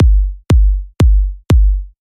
Например, имеем такую бочку:
Да и хвост шумный какой-то.
Атака у нее не выделена никак.
Kick_clean.mp3